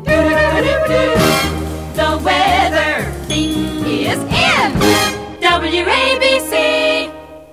produced in 1965 that featured four female voices
As you can hear, the famous chime at the end is not present.